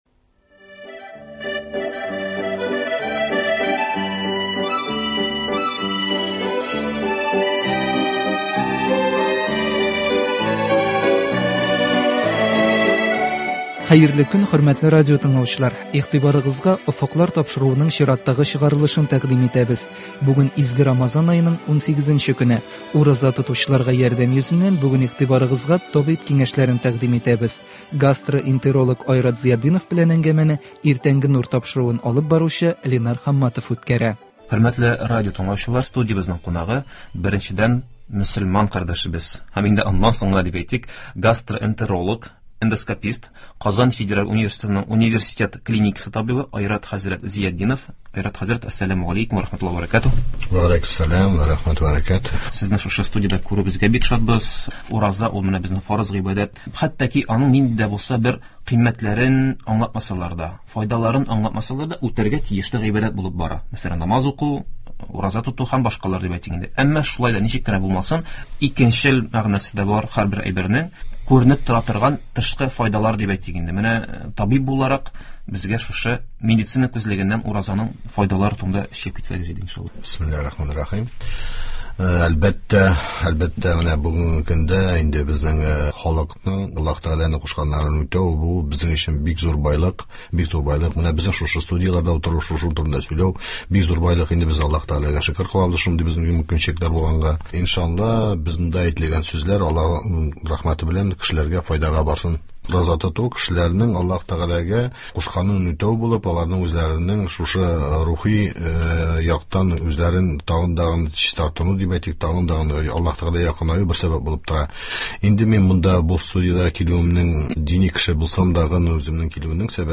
әңгәмәне